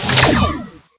.开火.ogg